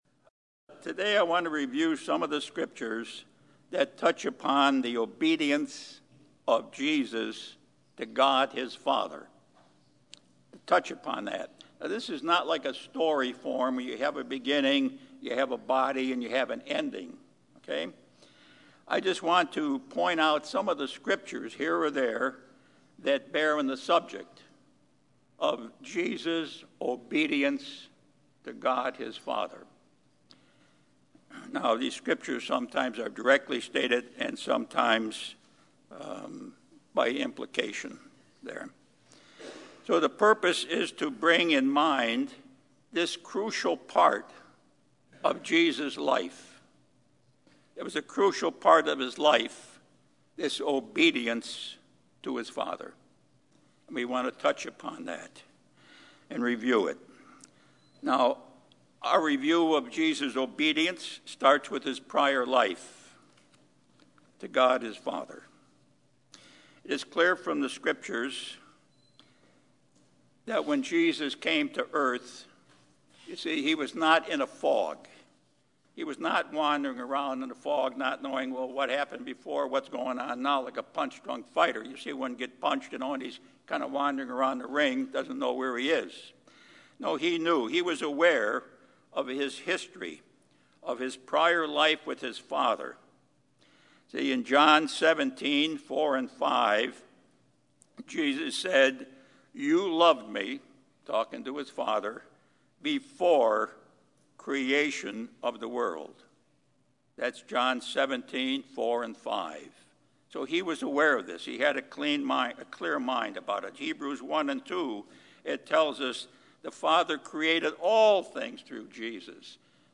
Sermons
Given in Los Angeles, CA Bakersfield, CA Las Vegas, NV Redlands, CA